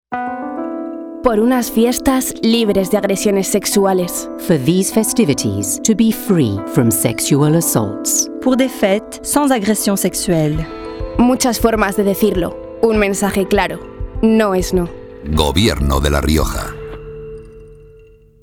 Elementos de Campaña Cuñas radiofónica Cuña de 20".